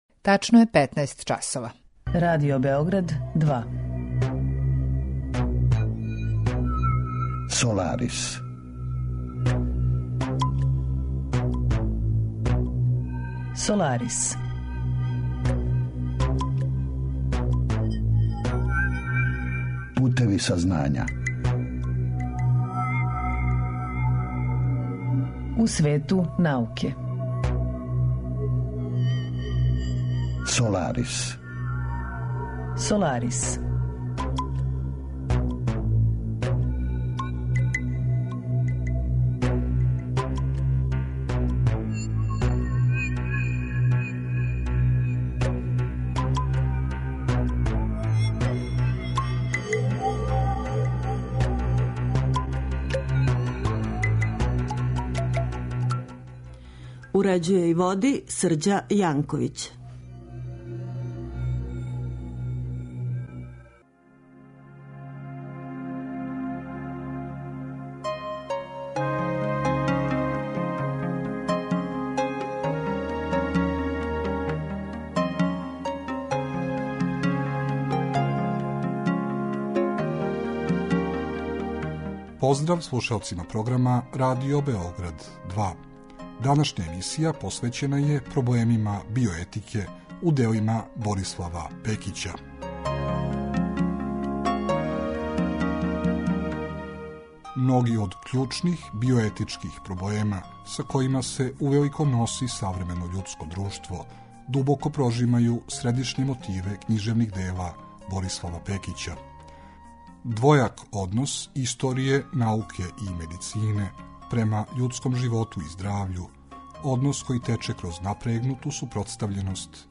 Разговор је први пут емитован 27. маја 2015. године.